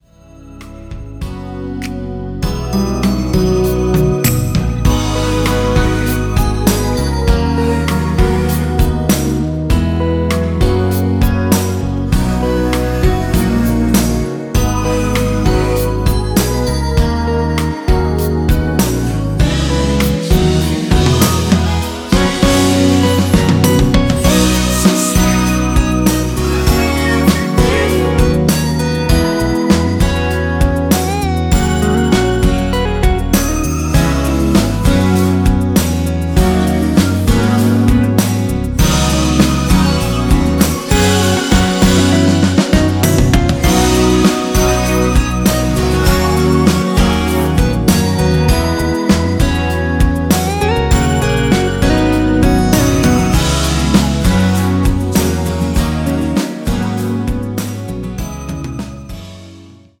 원키에서(-3)내린 코러스 포함된 MR입니다.
앞부분30초, 뒷부분30초씩 편집해서 올려 드리고 있습니다.